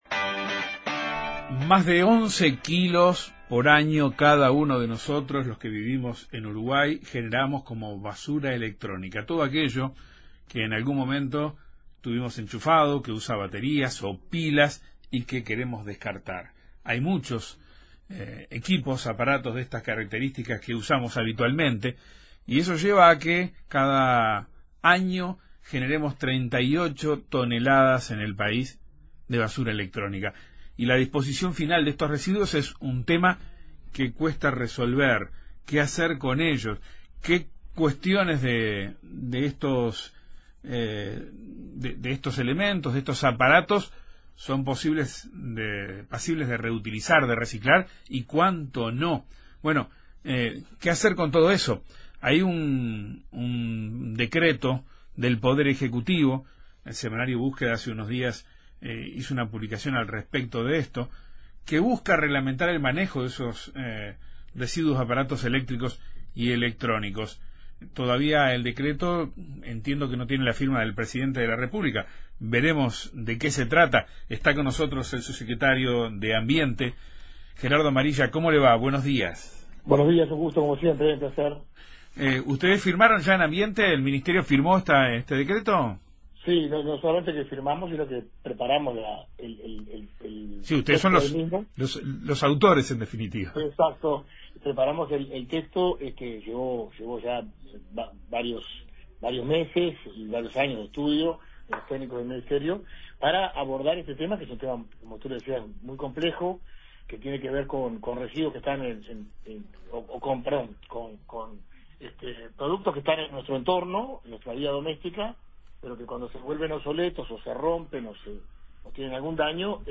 Amarilla recordó en Informativo Uruguay que en el país se producen casi 12 kilos de basura electrónica al año por habitante.